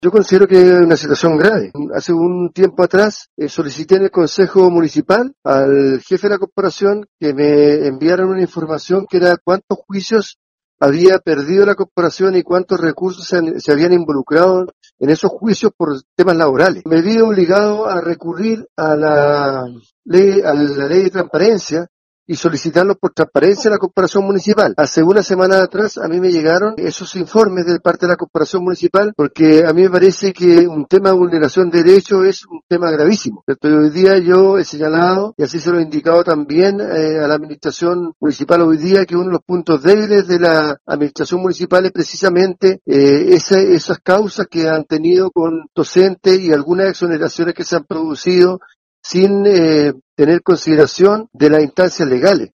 El concejal Jaime Márquez catalogó la situación como grave.